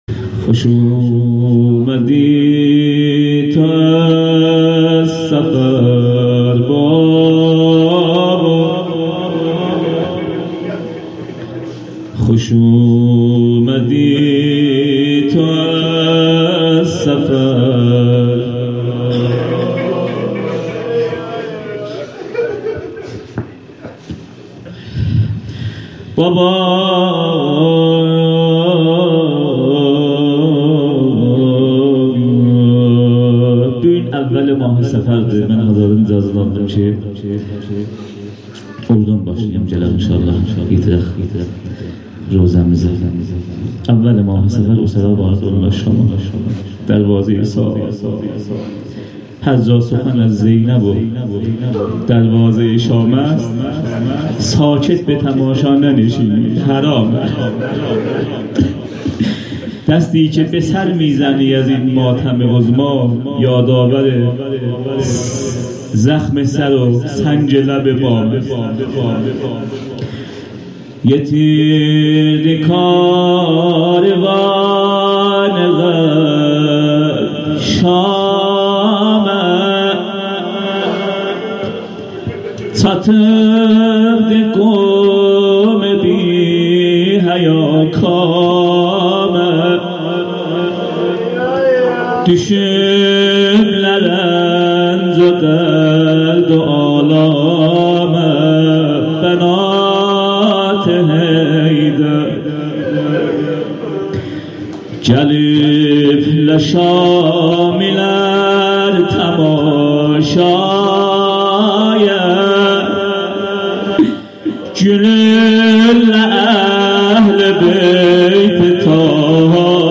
هیات هفتگی
مقدمه و روضه